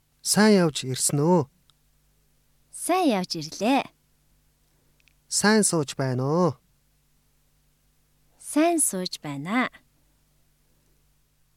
会話のスピ ー ドがゆっくりと通常の２パタ
サンプル音声２